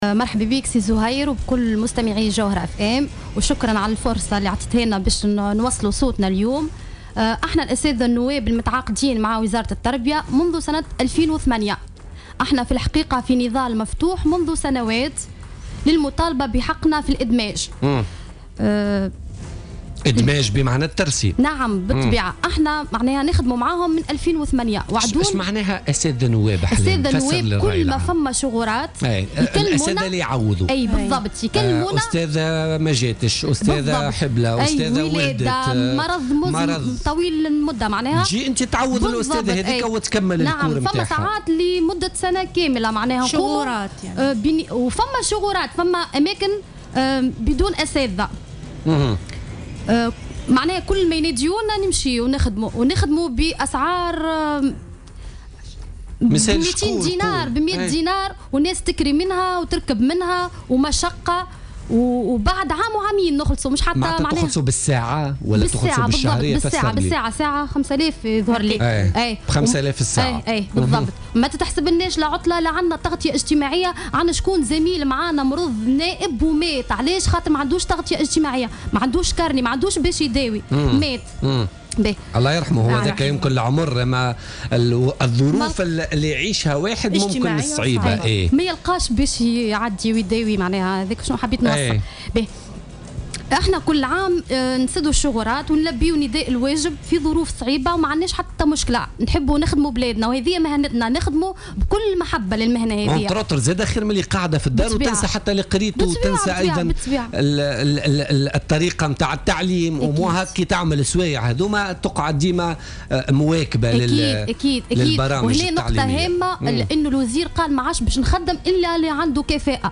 في تصريح للجوهرة أف أم خلال حضورها ضيفة في برنامج بوليتكا